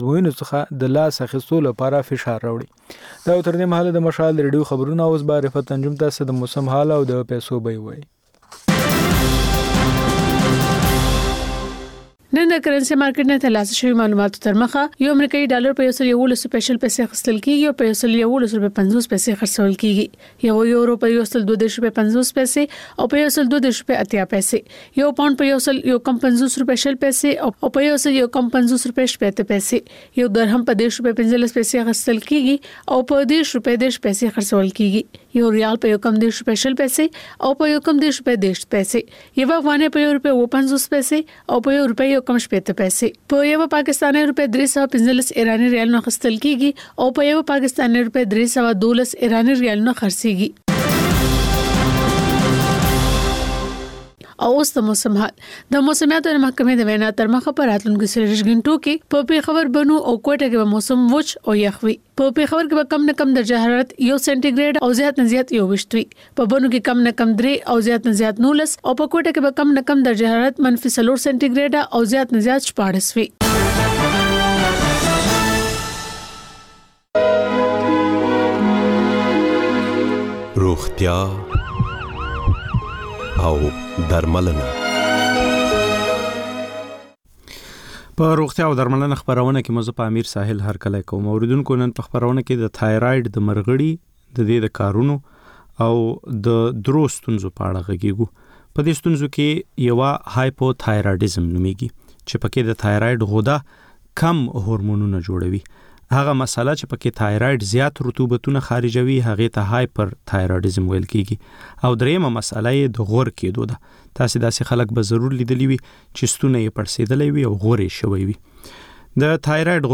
په روغتیا او درملنه خپرونه کې یو ډاکتر د یوې ځانګړې ناروغۍ په اړه د خلکو پوښتنو ته د ټیلي فون له لارې ځواب وايي.